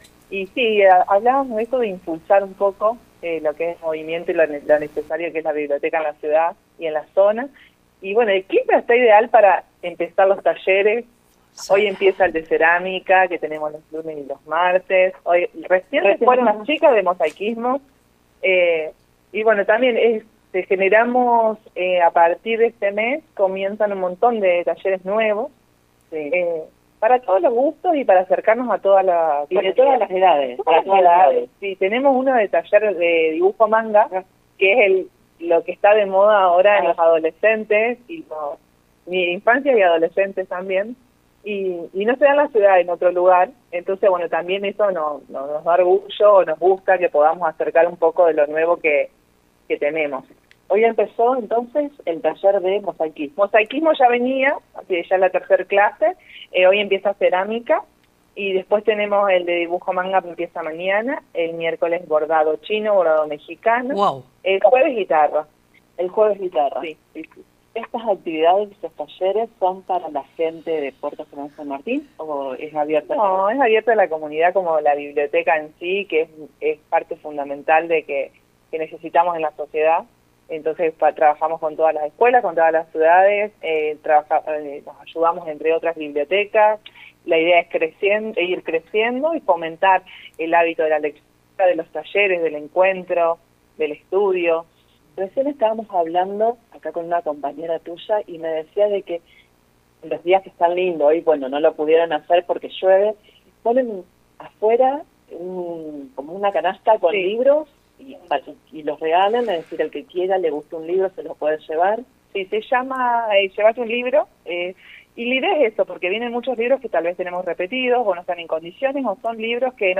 En diálogo con el móvil